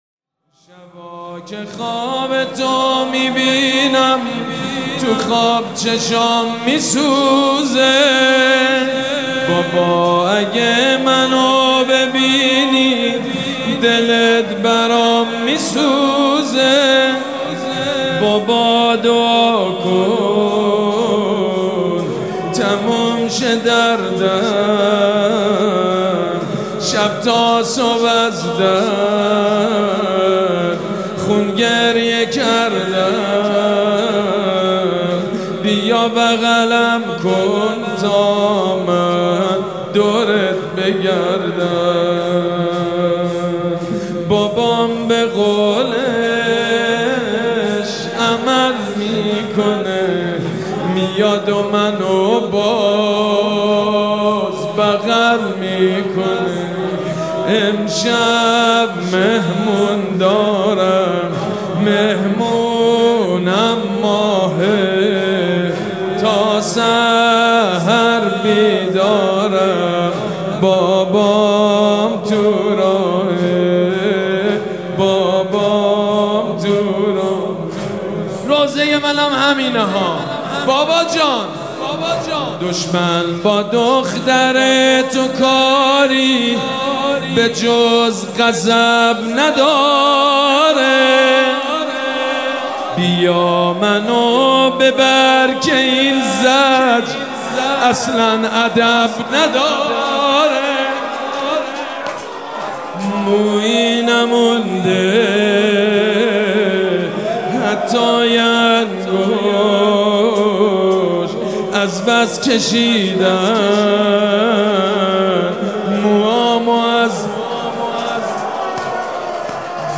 مداحی جدید